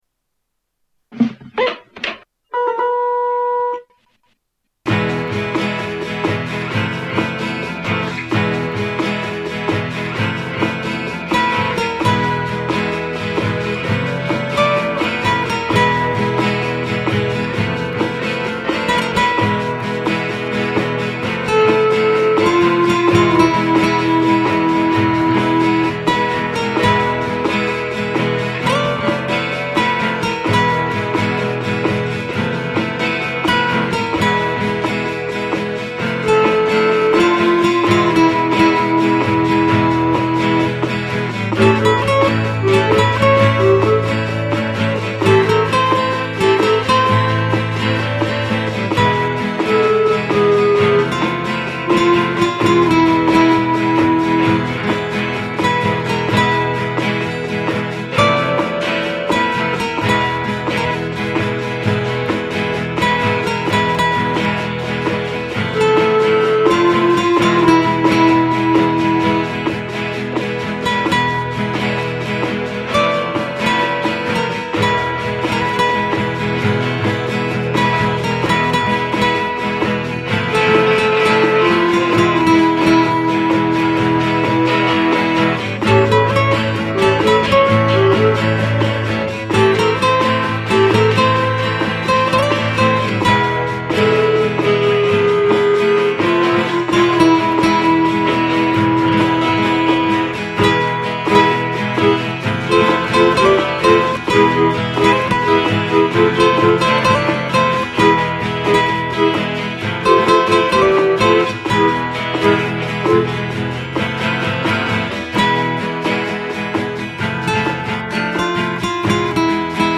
Many decades ago I had a little four track studio set up in my bedroom at my parents house.
Behold the lo fi wonder that is ‘Mexican standoff’
except the keyboard which features my buddy